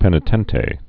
(pĕnĭ-tĕntā, -tē)